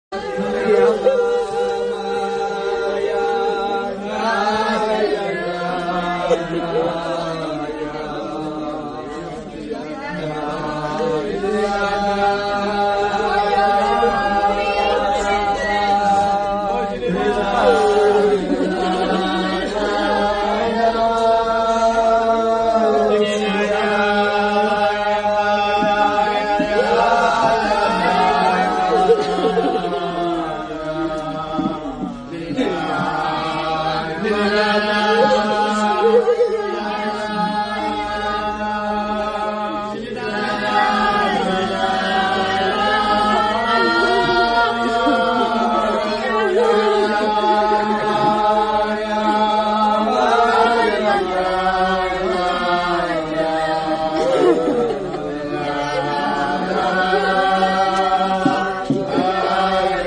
ניגון זה הולחן ע"י כ"ק אדמור מתולדות אהרן זצוק"ל בעל "דברי אמונה", וכפי ששומעים זה מביא התעוררות גדולה